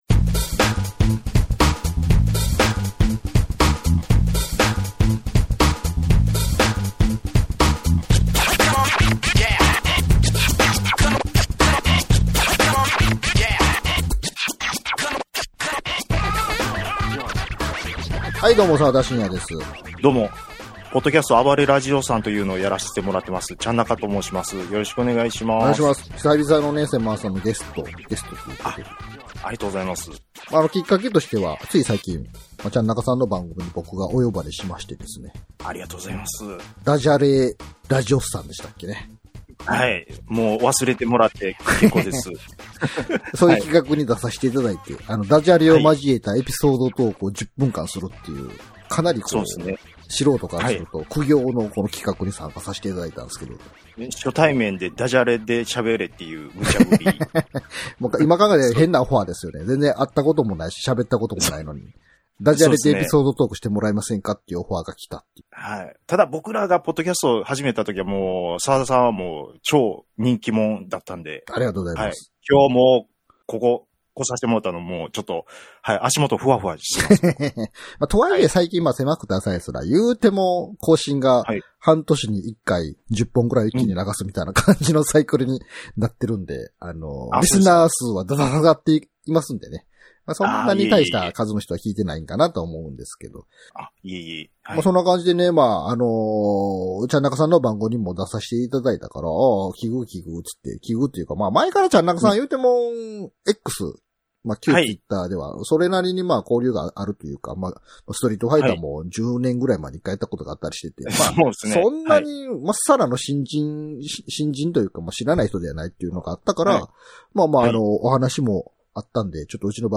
久々のゲスト回。